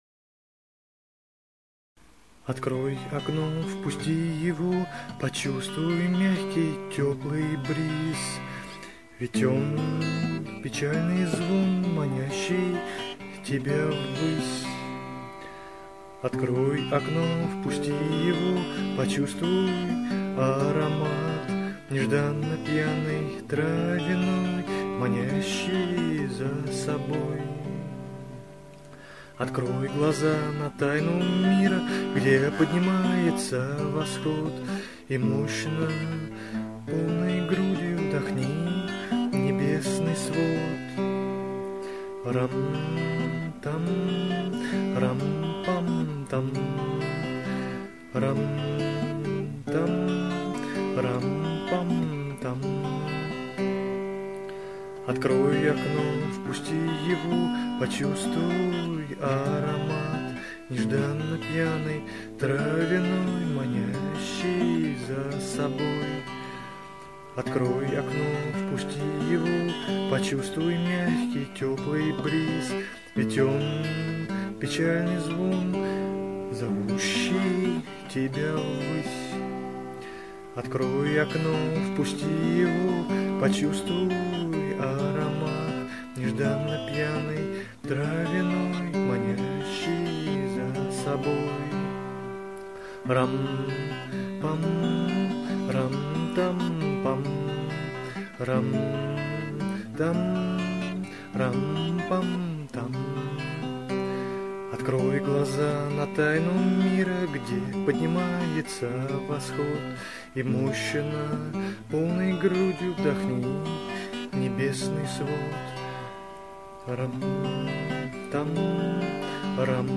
Позитивно такой йогический трек :)